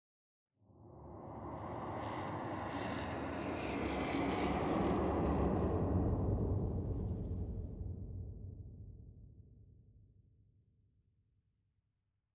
Minecraft Version Minecraft Version snapshot Latest Release | Latest Snapshot snapshot / assets / minecraft / sounds / ambient / nether / crimson_forest / addition3.ogg Compare With Compare With Latest Release | Latest Snapshot